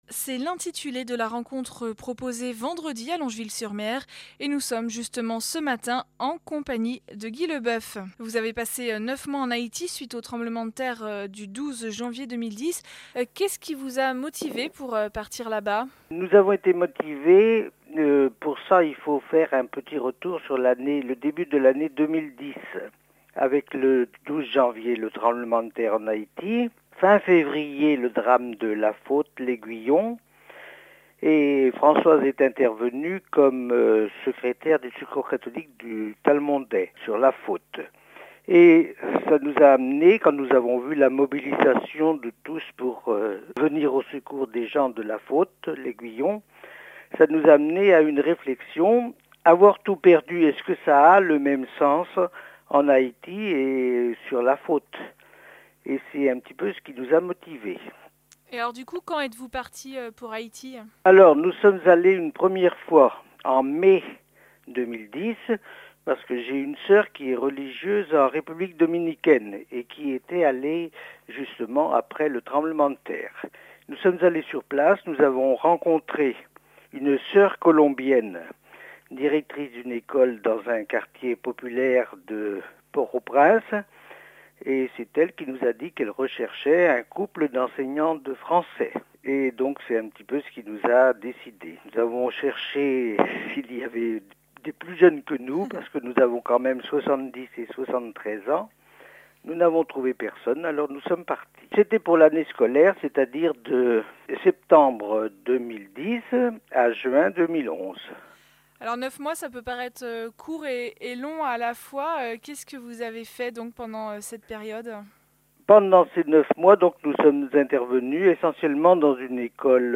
Revue de presse 2012 de la Mission Economique du Val du Loir – semaine 16